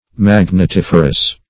Search Result for " magnetiferous" : The Collaborative International Dictionary of English v.0.48: Magnetiferous \Mag`net*if"er*ous\, a. [L. magnes, -etis + -ferous.]